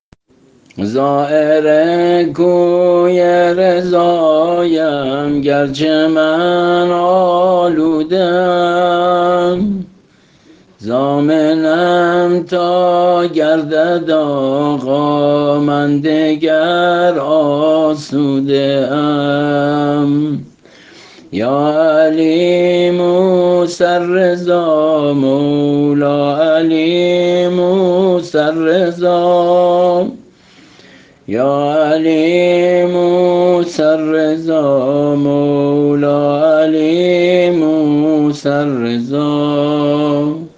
سرود تولد امام رضا(ع)